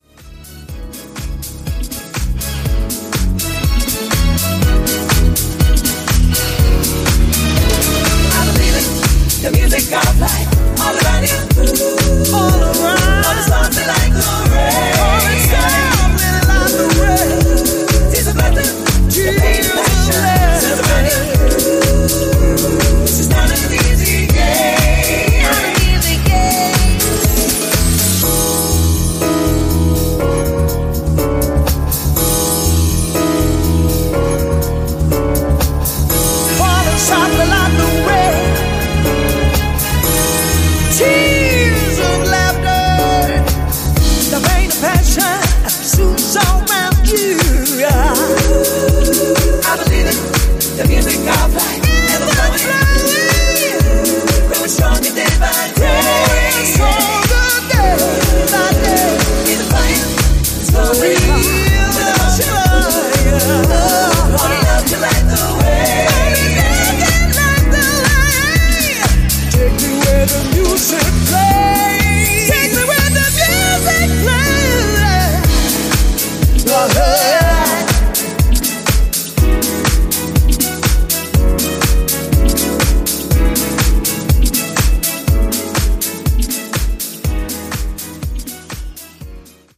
This package delivers two quality soulful house cuts
string laden and emotive